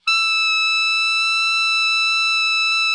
Index of /90_sSampleCDs/Giga Samples Collection/Sax/ALTO 3-WAY
ALTO  MF E 5.wav